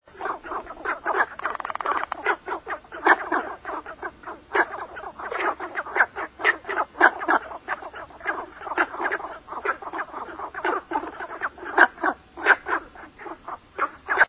Barking_frog.mp3